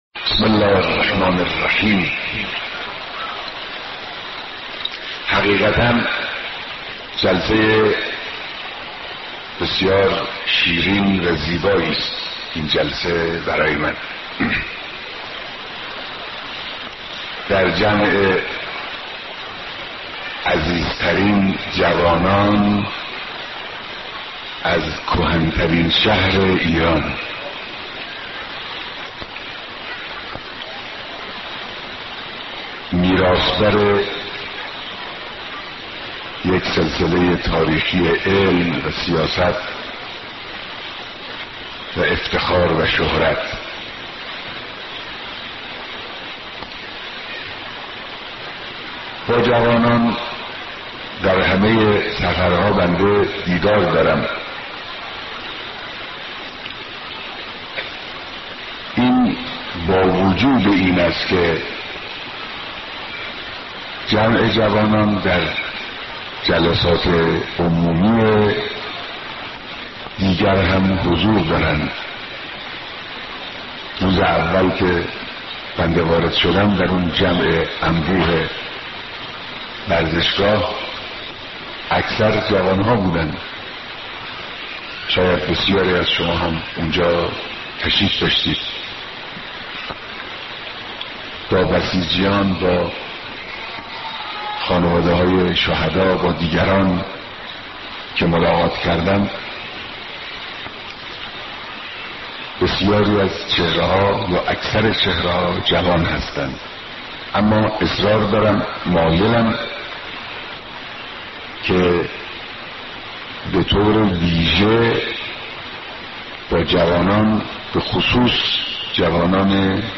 صوت کامل بیانات
سخنرانی